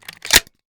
Decay/sound/weapons/arccw_ud/m1014/shell-insert-03.ogg at main
shell-insert-03.ogg